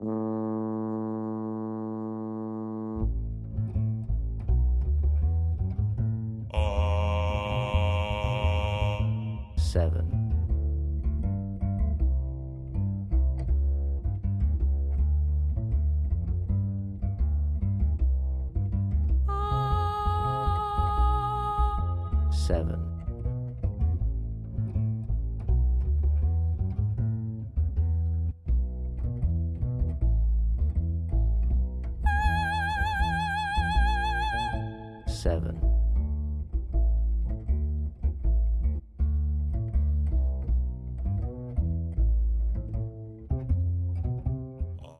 • Listening Exercise With Bass Only